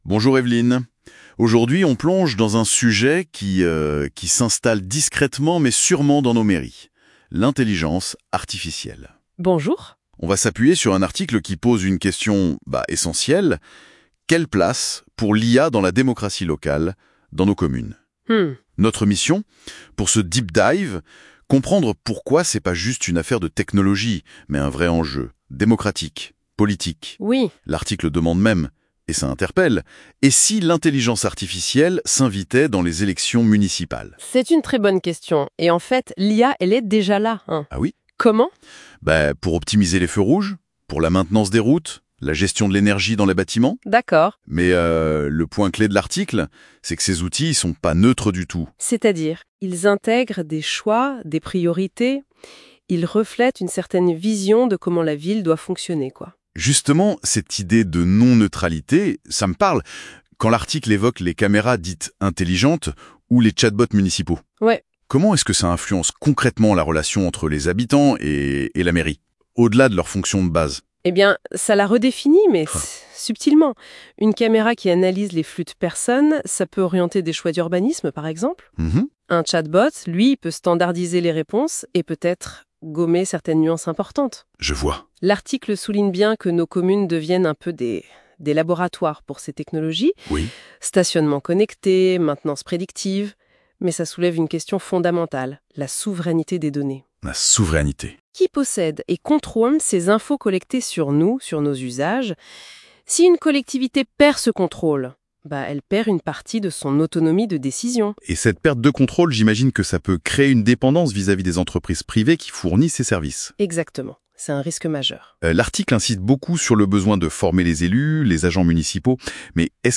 L'interview IA